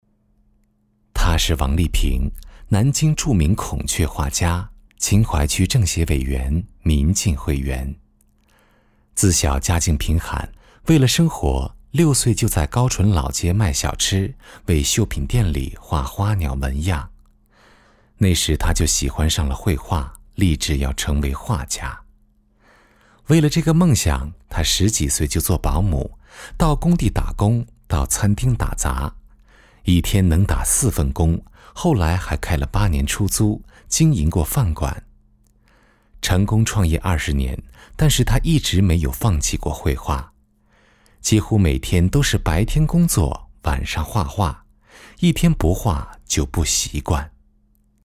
人物男287号（讲述）
自然诉说 人物专题
磁性浑厚男中音，可模仿任志宏，mg动画，脱口秀，记录片，专题等。